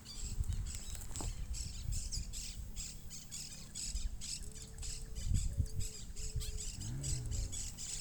Great Antshrike (Taraba major)
Detailed location: Dique Río Hondo
Condition: Wild
Certainty: Recorded vocal